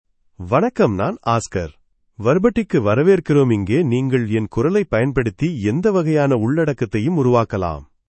Oscar — Male Tamil AI voice
Oscar is a male AI voice for Tamil (India).
Voice sample
Male
Oscar delivers clear pronunciation with authentic India Tamil intonation, making your content sound professionally produced.